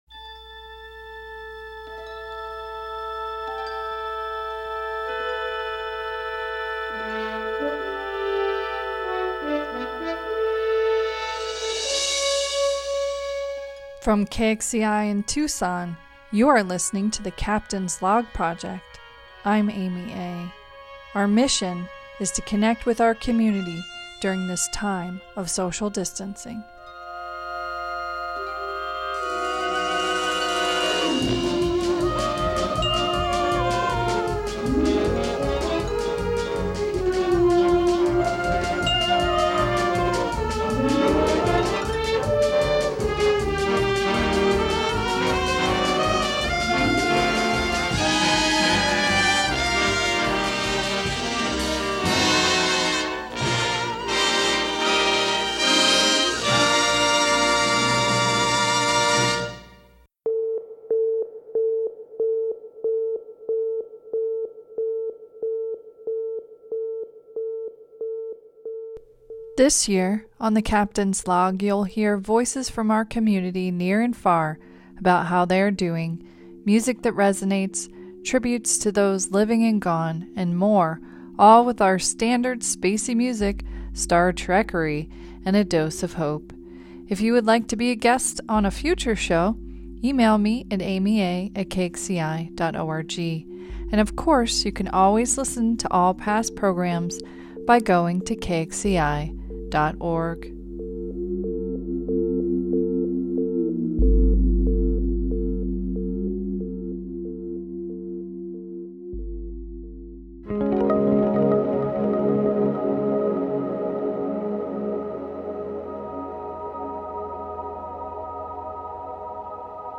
In this episode we hear our first submitted log on our new Captain's Log Voicemail! We also explore the work and inspiration of Inaugural Poet Amanda Gorman. As always, we put it together with spacey music and a dose of hope.